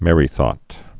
(mĕrē-thôt)